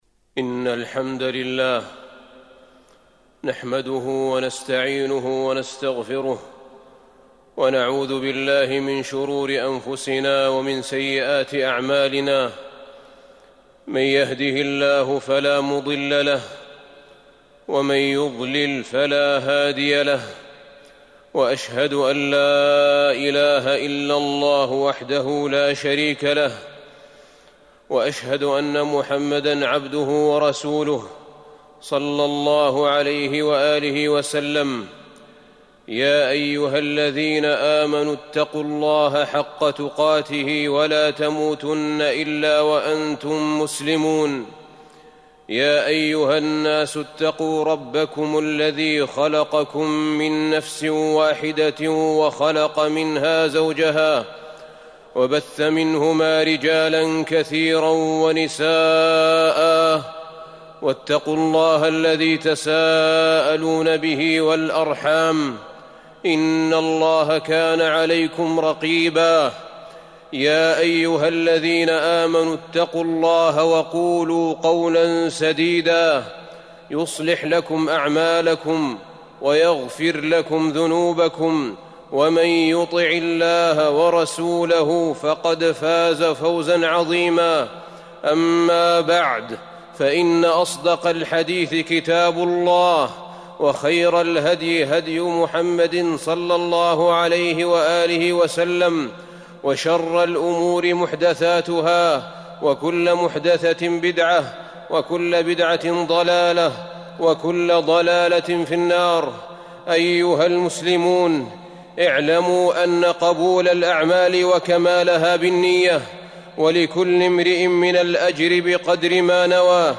تاريخ النشر ٢٤ ذو الحجة ١٤٤١ هـ المكان: المسجد النبوي الشيخ: فضيلة الشيخ أحمد بن طالب بن حميد فضيلة الشيخ أحمد بن طالب بن حميد لوامع الكلم الجوامع